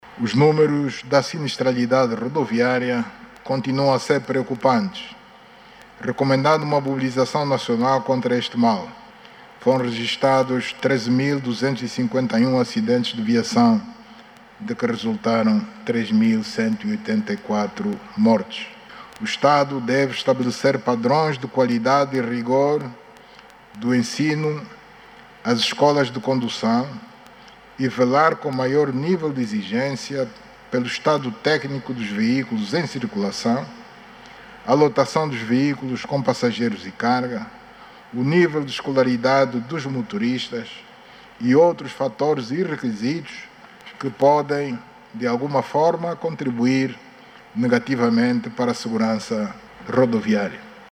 João Lourenço, que falava numa mensagem sobre o Estado da Nação, na abertura do novo ano parlamentar, lamentou que, no último ano, tenham sido registados mais de treze mil acidentes de viação, de que resultaram na morte de mais de três mil pessoas. O Chefe de Estado angolano, recomenda, por isso, que se estabeleça padrões de qualidade e rigor do ensino às escolas de condução.